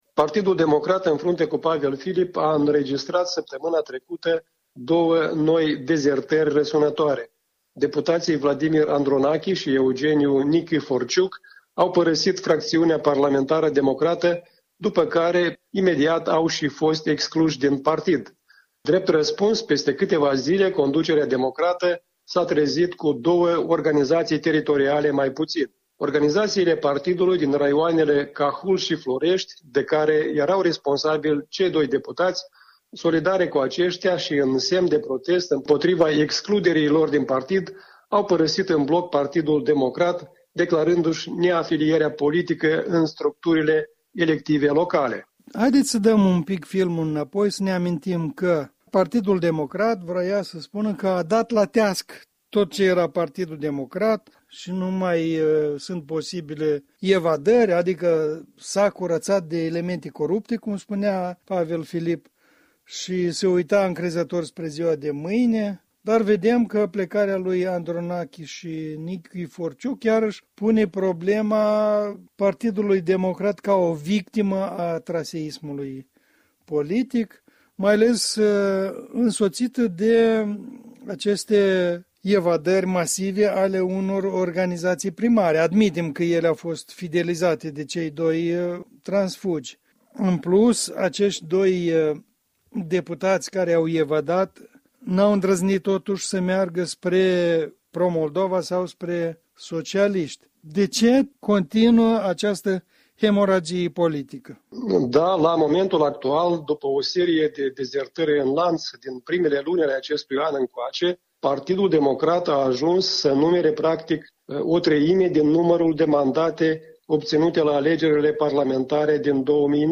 Punct de vedere săptămânal, în dialog, despre ultimii doi evadați din tabăra democrată.